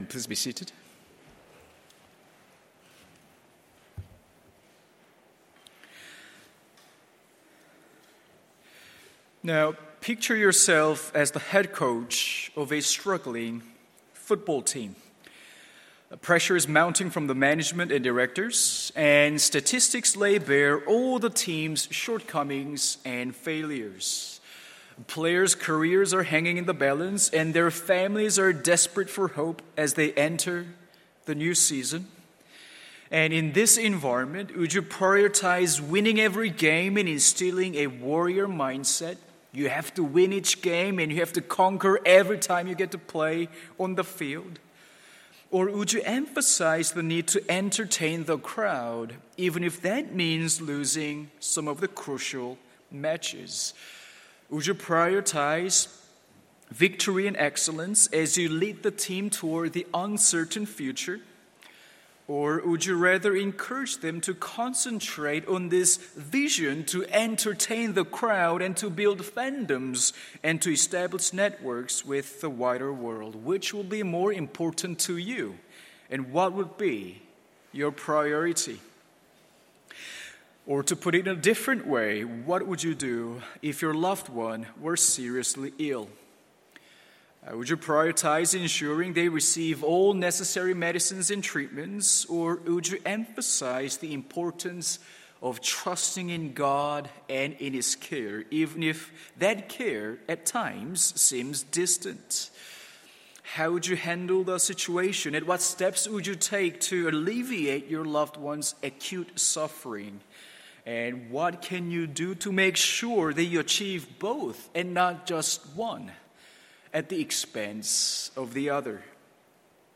MORNING SERVICE Exodus 3:1-15